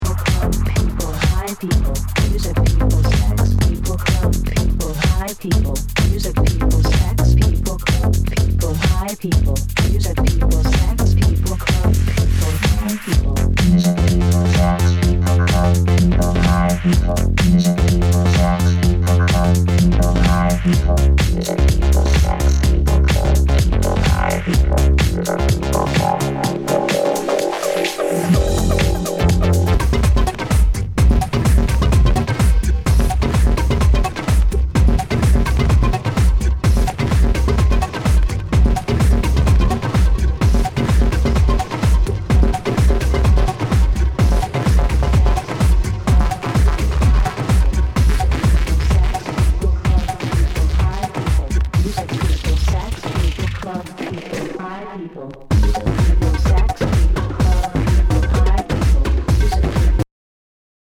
[ Genre ] HOUSE/TECHNO/ELECTRO
ナイス！エレクトロ・ハウス！